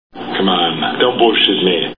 Total Recall Movie Sound Bites